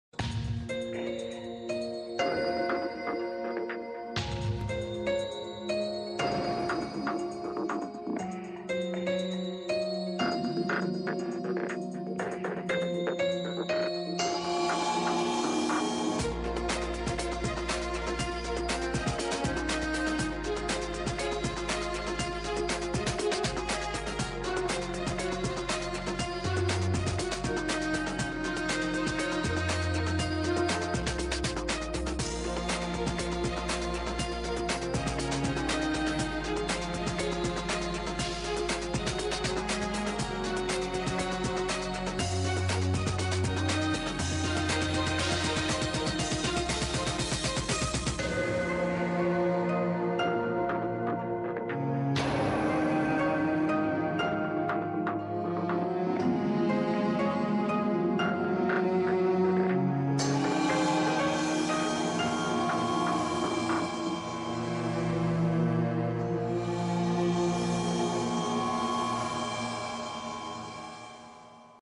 شارة البداية